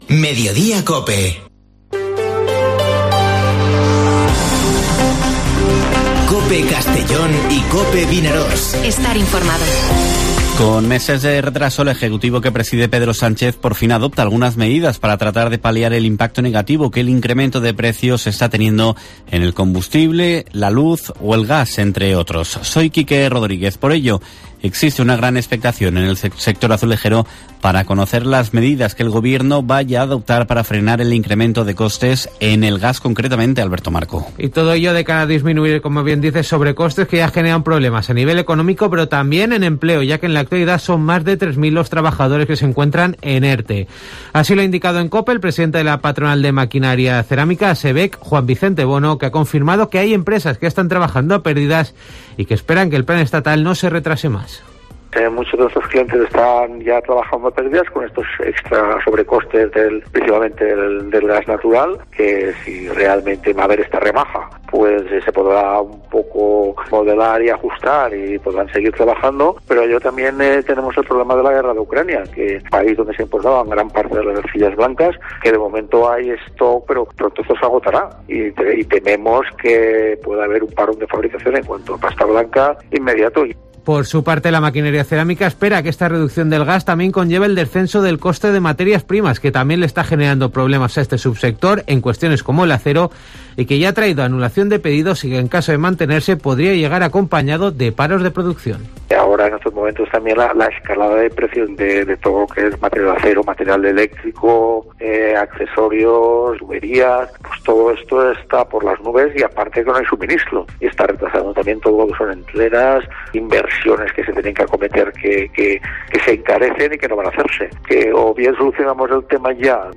Informativo Mediodía COPE en la provincia de Castellón (29/03/2022)